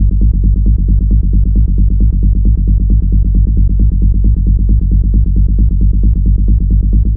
BL 134-BPM G.wav